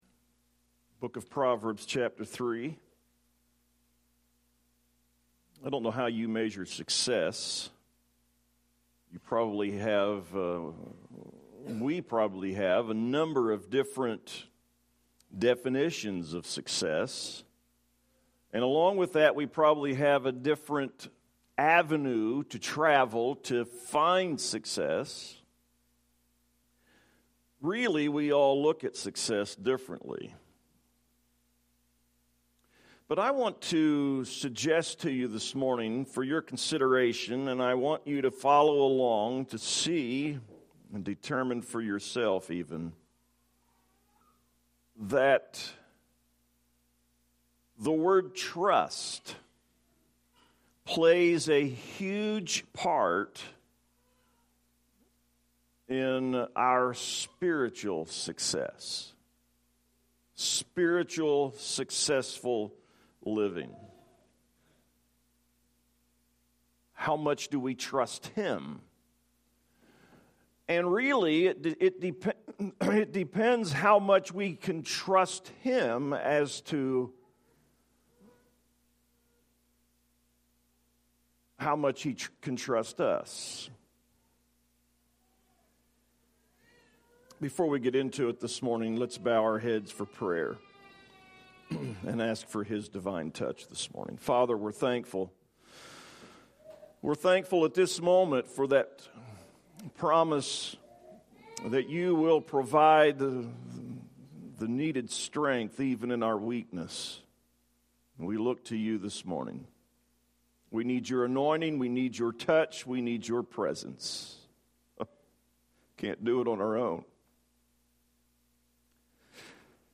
Save Audio A sermon